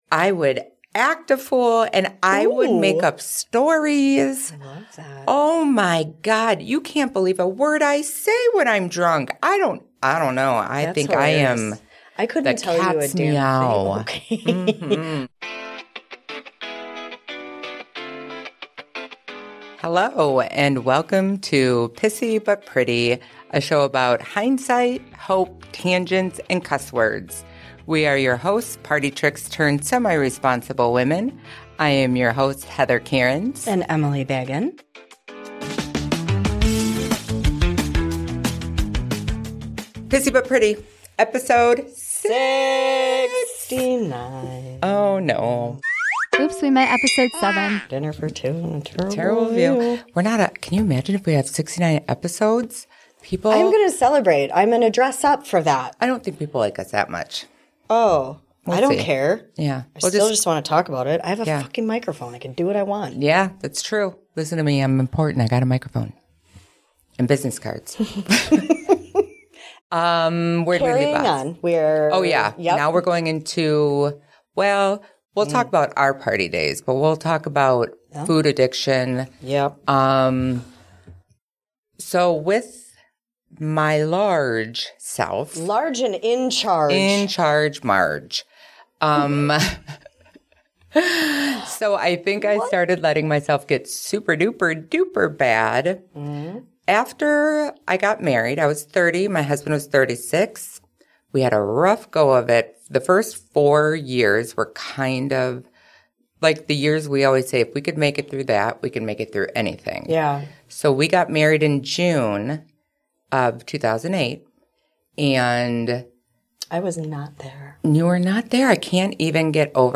These once-upon-a-time party queens spin tales of their escapades with a cheeky blend of humor and brutal honesty. From the battle with booze and boys to finding hope amidst the hangovers, it’s a candid look at how past poor choices don't have to define you.